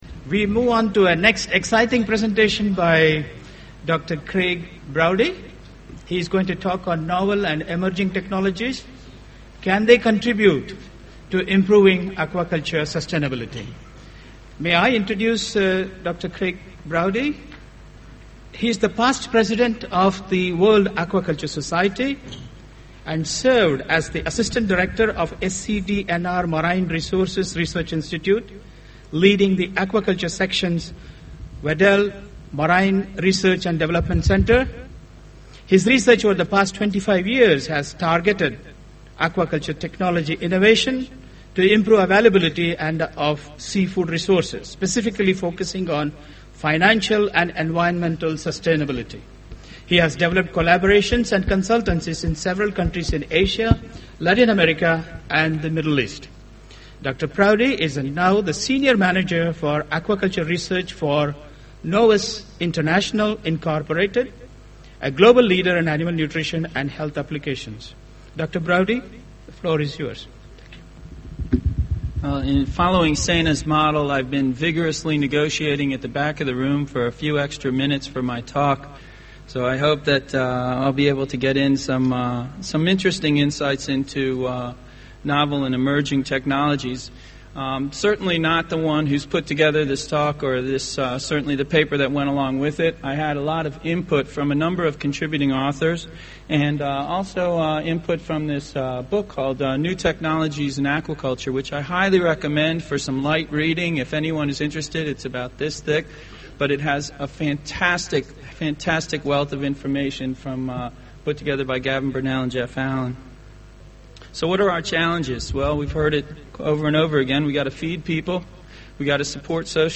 Presentation on the role of emerging technologies in sustainable aquaculture